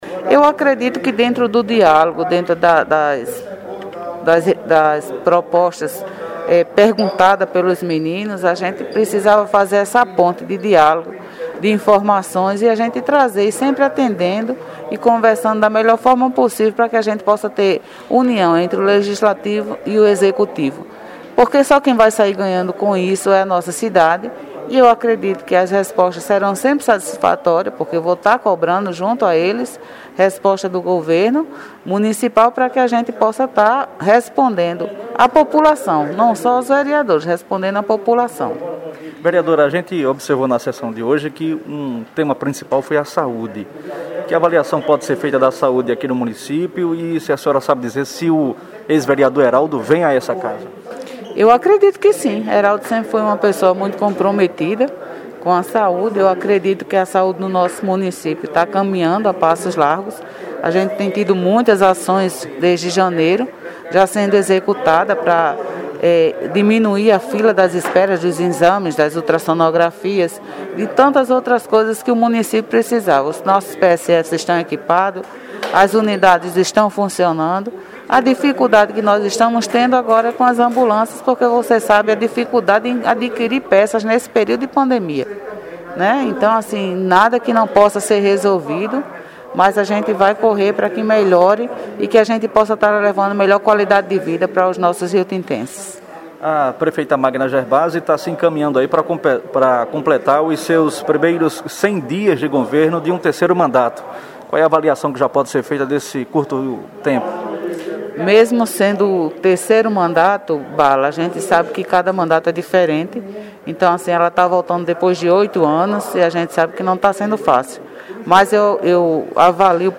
A líder do governo na Câmara de Vereadores, vereadora Claudecir Braz (PP), na sessão ordinária da última quarta-feira (17), disse que aposta no “diálogo” para união entre o Legislativo e o Executivo, no intuito do trabalho prosperar na cidade de Rio Tinto. Um dos temas mais debatidos na sessão foi sobre a saúde.